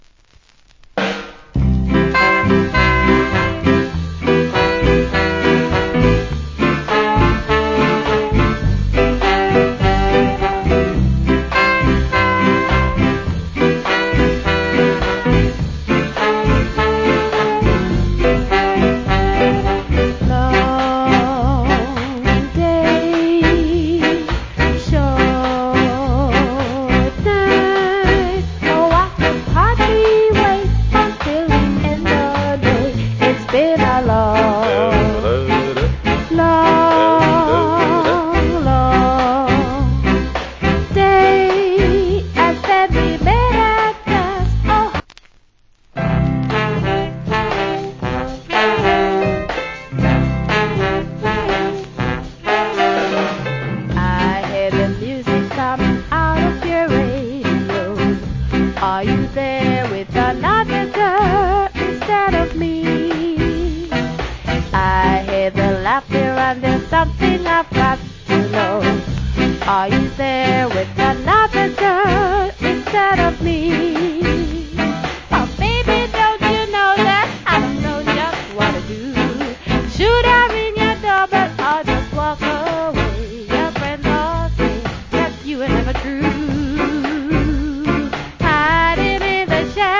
Great Female Rock Steady Vocal.